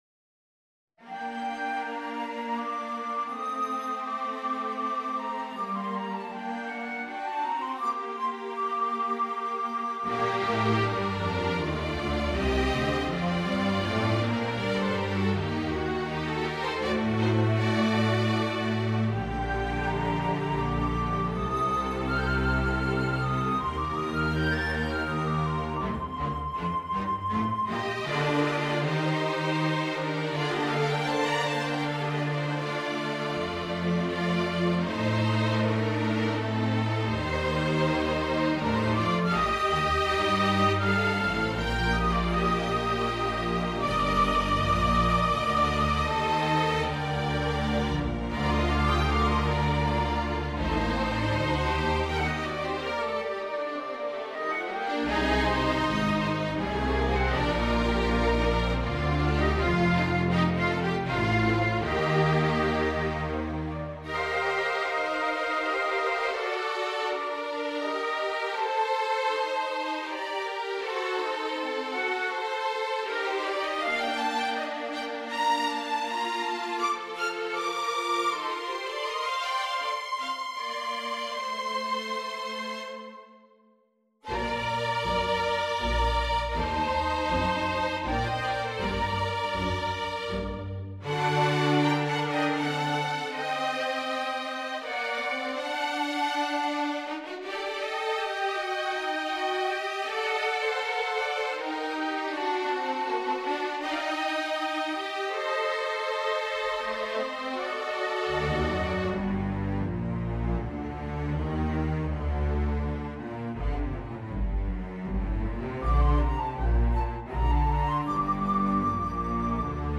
Mock-ups generated by NotePerformer can be heard below (to listen, click on the white arrowhead on the left of the bar).
A tonal and melodic work of three movements with a playing time of 9 minutes:
fantasia-for-flute-and-strings-ii.mp3